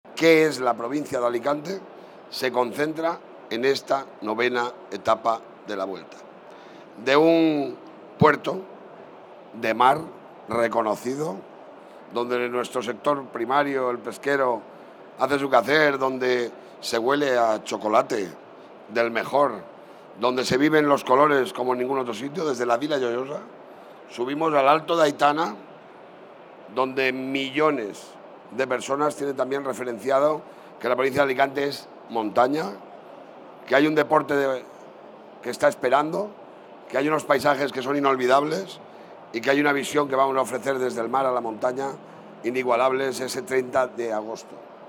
El presidente de la Diputación de Alicante, Toni Pérez, ha presentado esta mañana en el marco de la Feria Internacional de Turismo de Madrid todos los detalles de esta intensa jornada deportiva, con más de 5.100 metros de desnivel, en la que los ciclistas deberán enfrentarse a seis puertos de montaña, dos de ellos de primera categoría, dos de segunda y otros dos de tercera.
Corte-Toni-Perez-Presentacion-Etapa-Vuelta-Ciclista-a-Espana.mp3